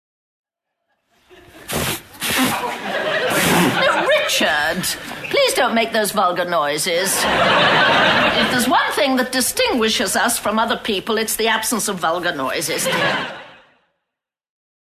From: Keeping Up Appearances Episode: The Father Christmas Suit (1991) (Season 2, Episode 11) (blowing nose) –Richard!
vulgarnoises.mp3